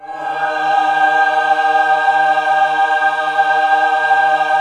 Index of /90_sSampleCDs/USB Soundscan vol.28 - Choir Acoustic & Synth [AKAI] 1CD/Partition B/06-MENWO CHD